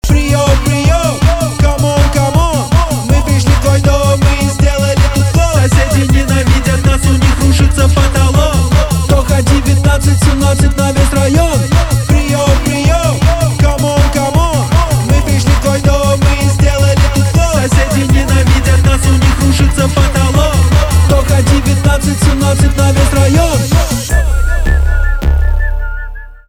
электроника
басы
свист